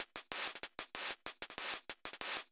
Index of /musicradar/ultimate-hihat-samples/95bpm
UHH_ElectroHatA_95-02.wav